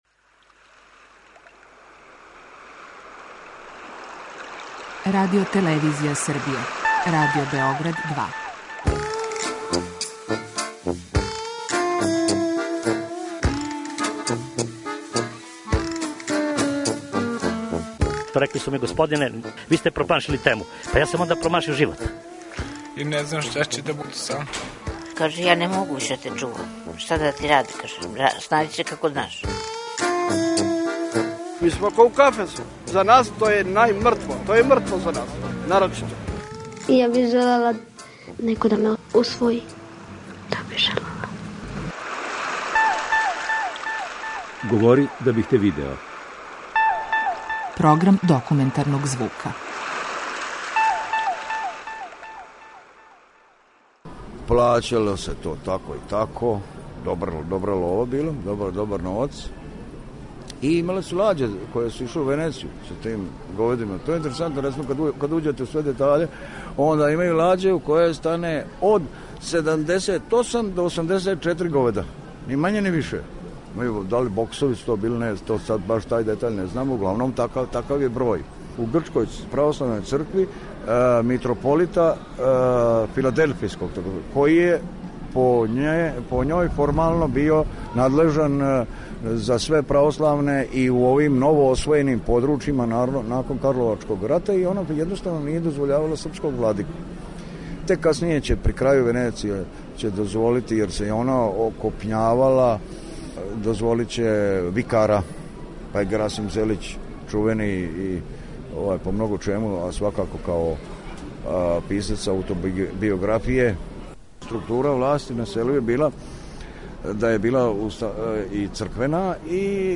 Документарни програн